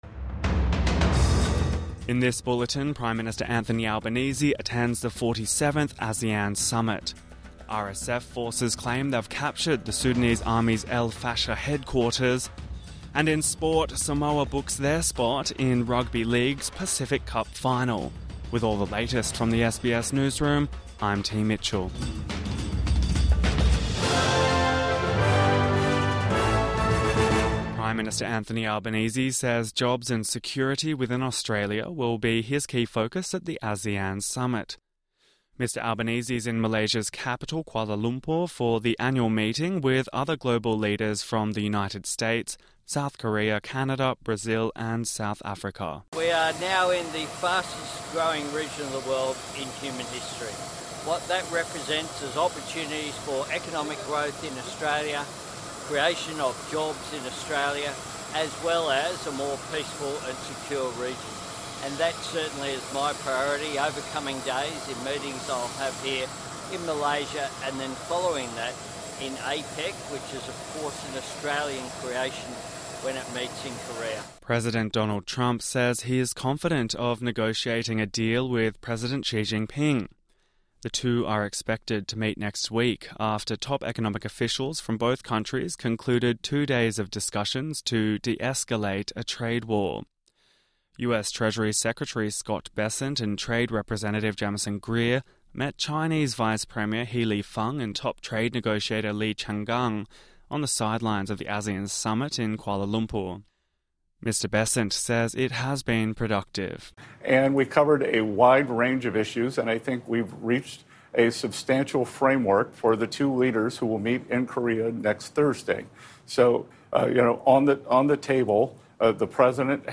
Morning News Bulletin 27 October 2025